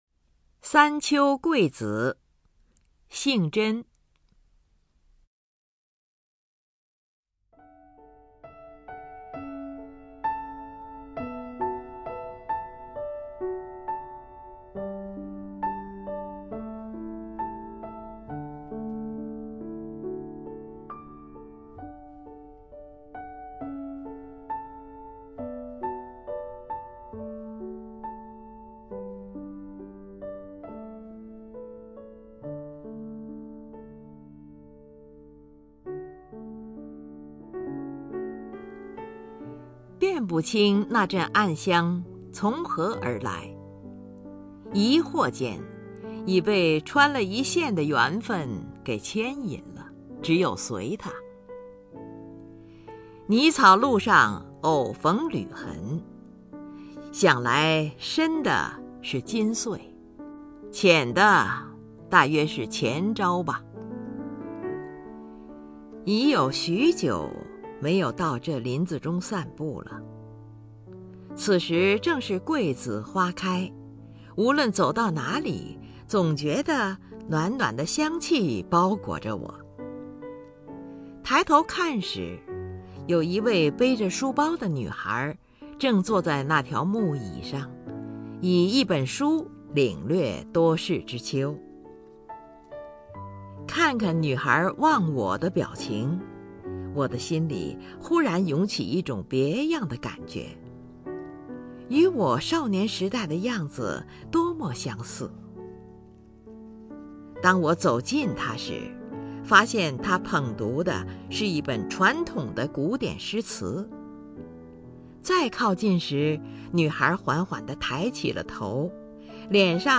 林如朗诵：《三秋桂子》(杏臻)
名家朗诵欣赏 林如 目录